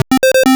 retro_synth_beeps_groove_06.wav